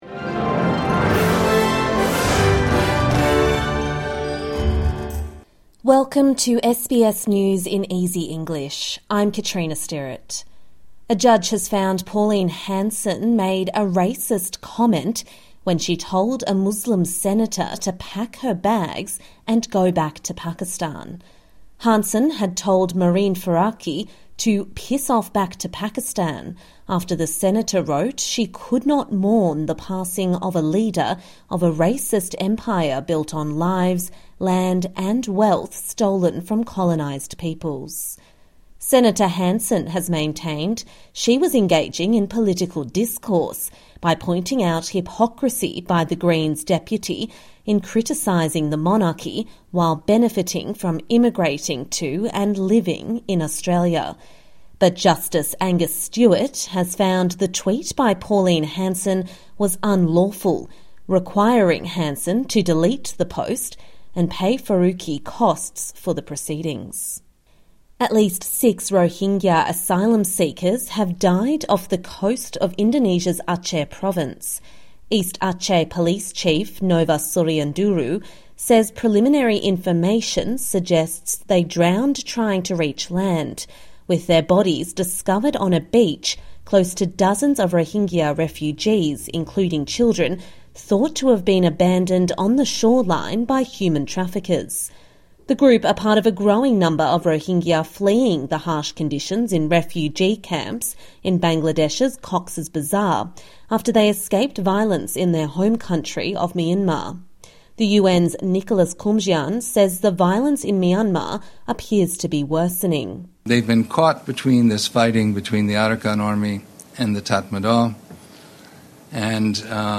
A daily 5 minute news wrap for English learners and people with a disability.